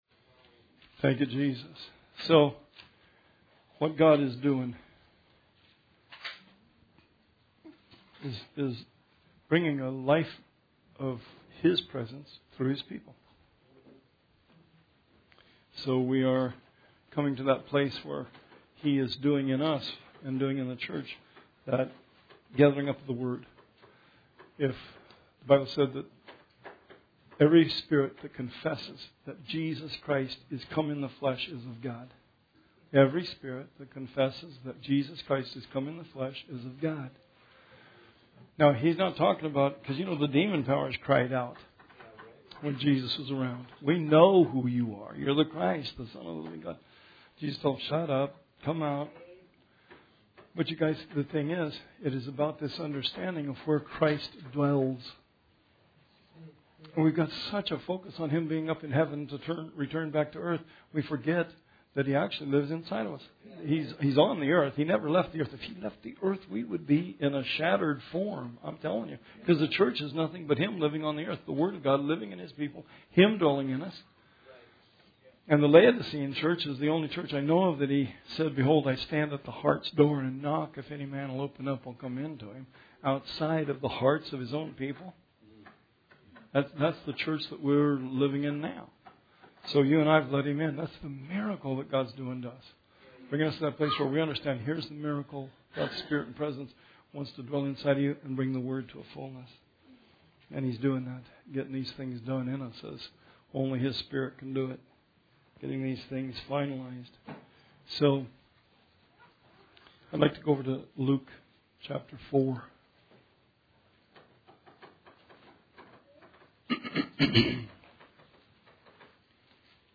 Bible Study 2/27/19